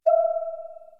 warning.mp3